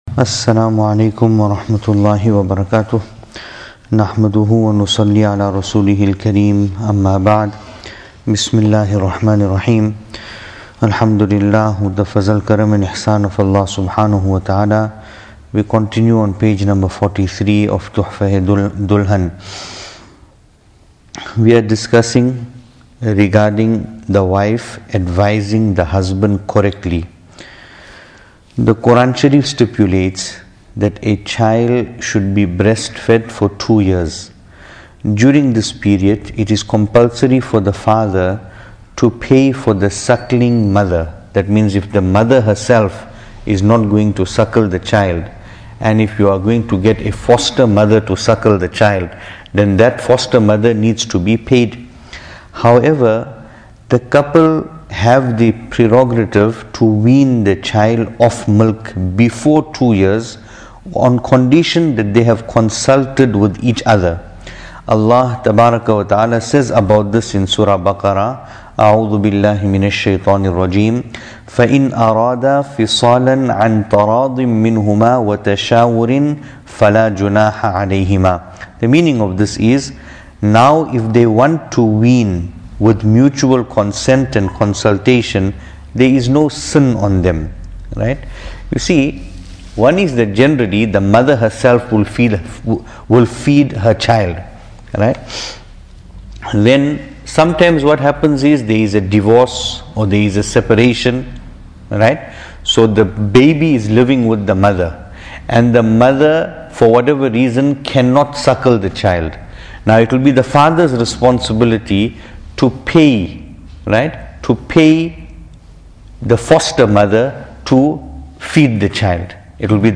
Venue: Pietermaritzburg | Series: Tohfa-e-Dulhan